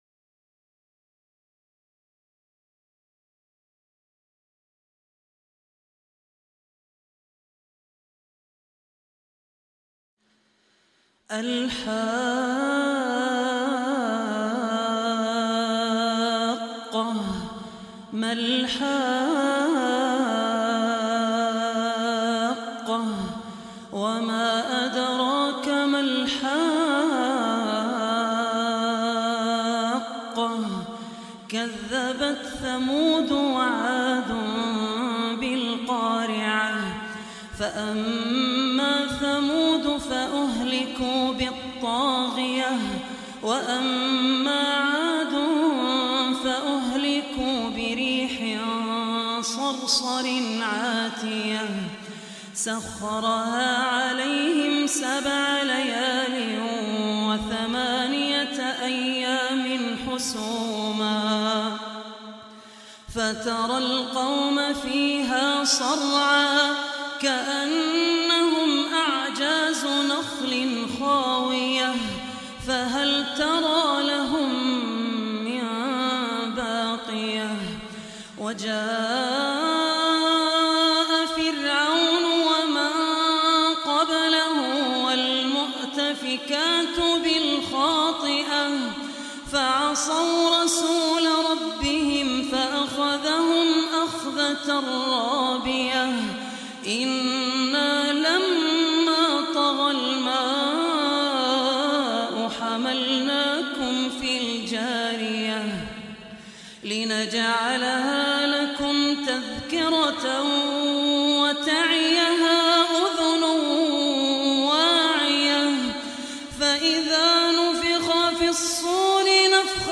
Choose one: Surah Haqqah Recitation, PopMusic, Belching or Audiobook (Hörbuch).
Surah Haqqah Recitation